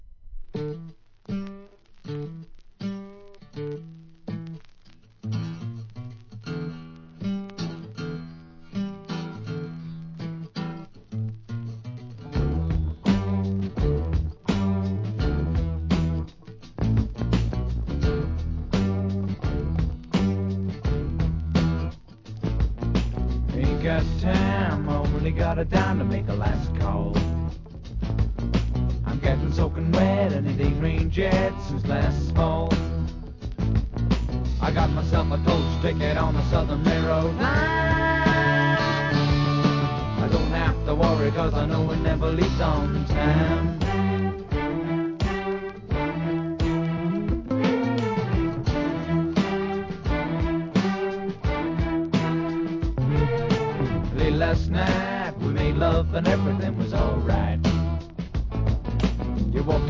¥ 330 税込 関連カテゴリ SOUL/FUNK/etc...